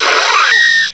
cry_not_durant.aif